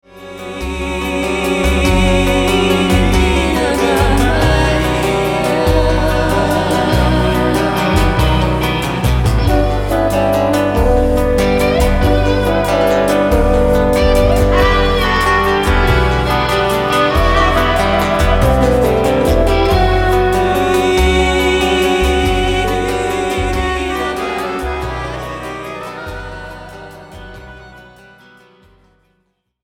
- Recorded and mixed at AVAF Studios, Zurich, Switzerland